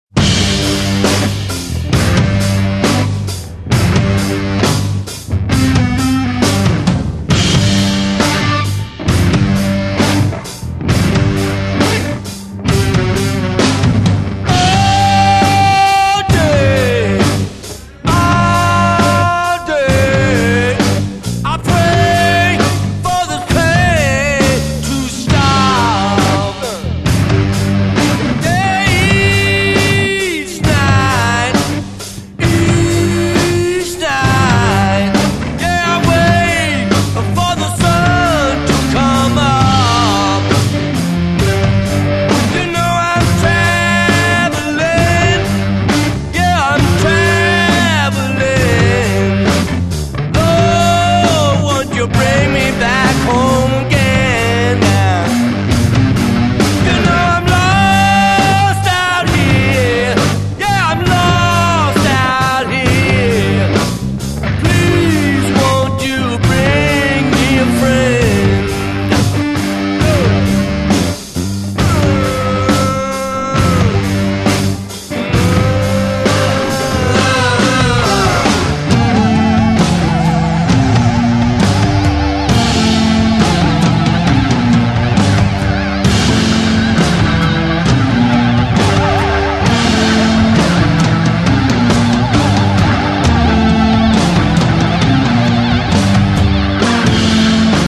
藍調音樂